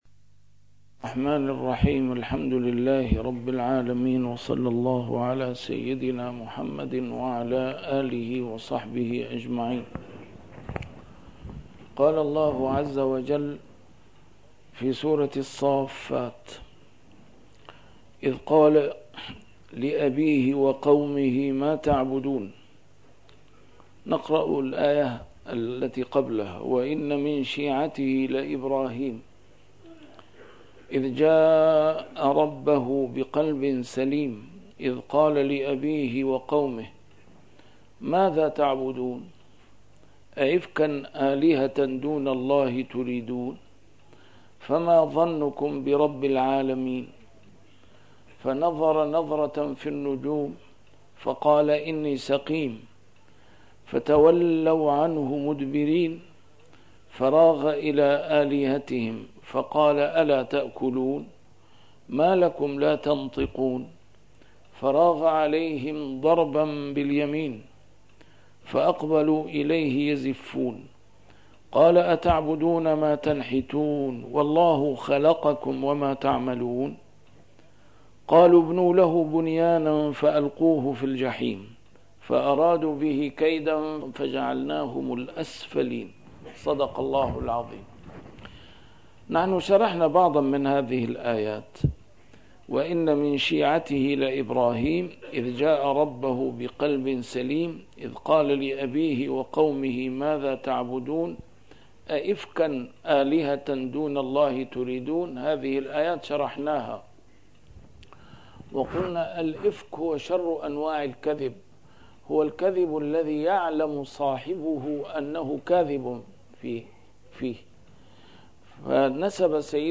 A MARTYR SCHOLAR: IMAM MUHAMMAD SAEED RAMADAN AL-BOUTI - الدروس العلمية - تفسير القرآن الكريم - تسجيل قديم - الدرس 454: الصافات 085-098